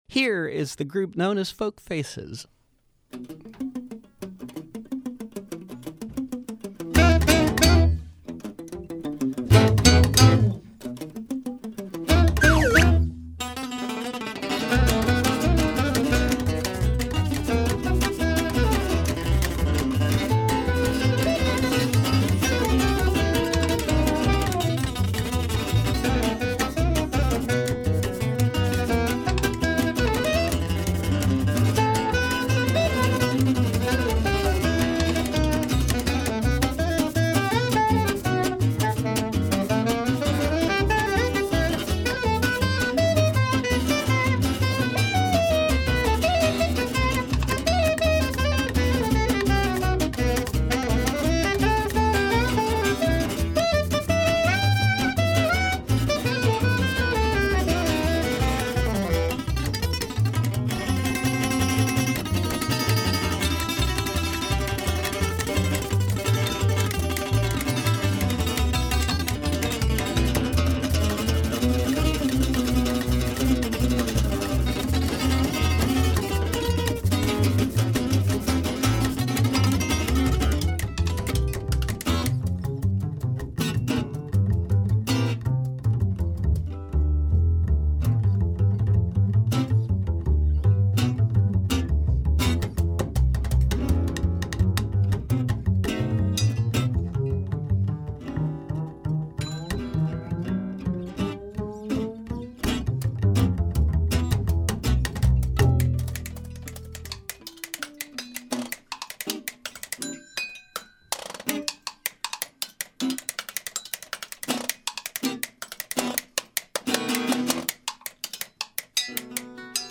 Buffalo-based quartet